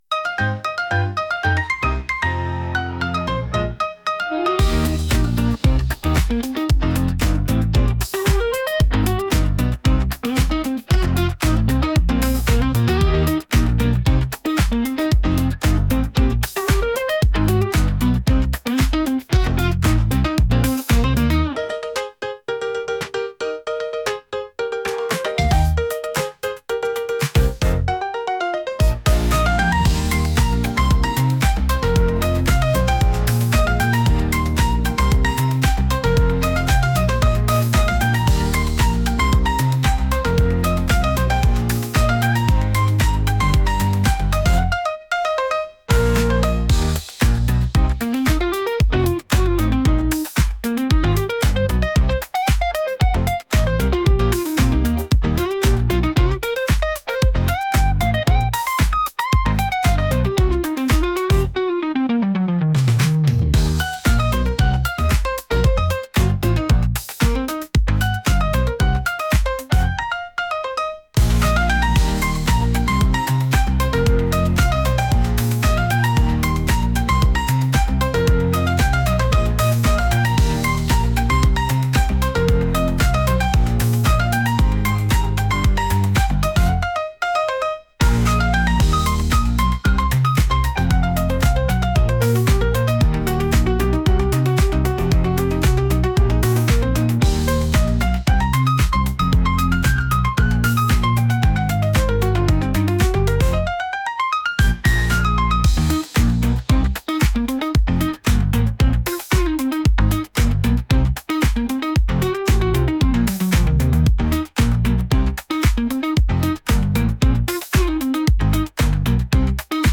探し物をするようなピアノ曲です。